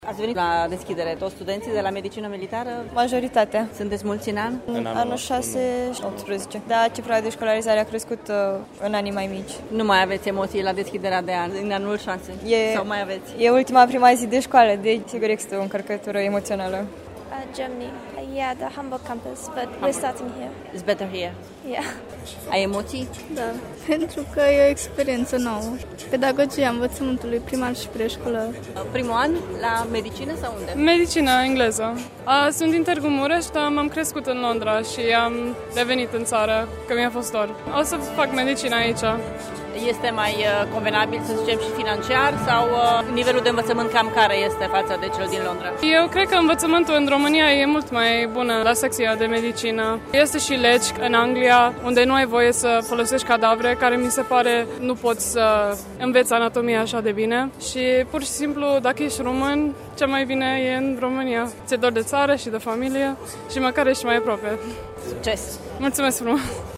Palatul Culturii din Târgu Mureș a fost astăzi arhiplin de studenți și părinți care nu au fost lipsiți de emoții. Unii sunt tineri români veniți din Londra pentru a studia Medicină la Târgu Mureș: